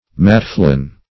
matfelon - definition of matfelon - synonyms, pronunciation, spelling from Free Dictionary Search Result for " matfelon" : The Collaborative International Dictionary of English v.0.48: Matfelon \Mat"fel*on\, n. [W. madfelen.]